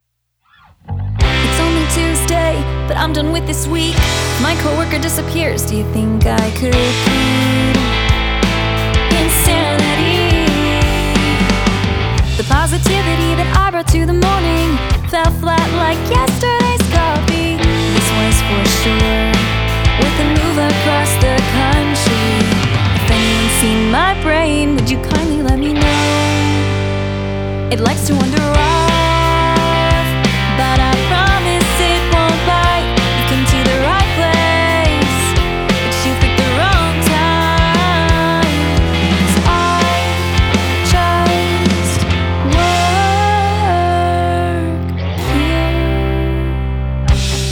Alternative / Punk Rock Opera
punk-rock ballad